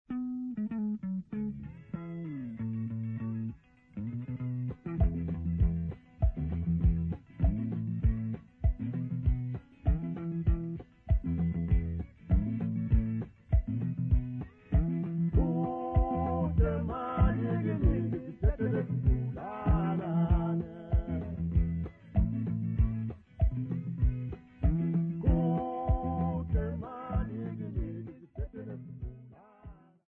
Folk music
Sacred music
Field recordings
Africa South Africa Soweto, Guateng Province sa
Indestructible beat of Soweto with bass guitar, guitar, bell and drums accompaniment